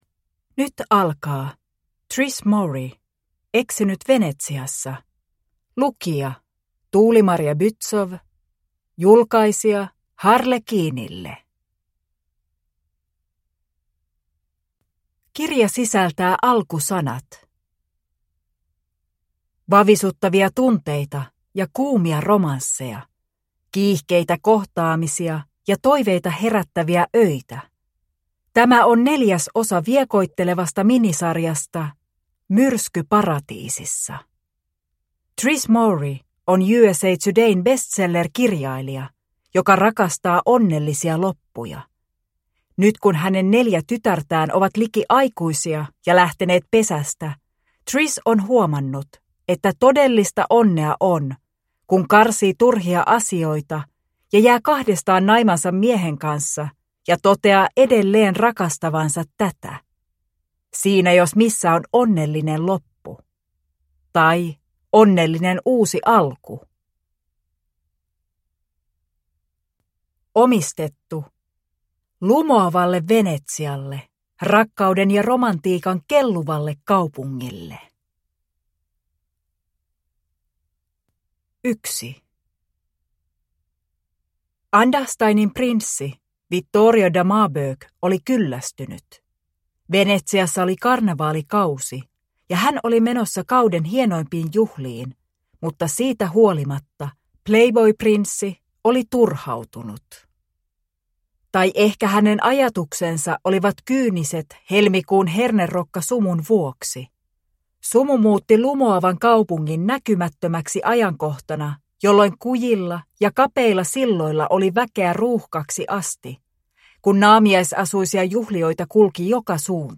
Eksynyt Venetsiassa (ljudbok) av Trish Morey